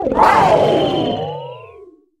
Cri de Miraidon Mode Ultime dans Pokémon HOME.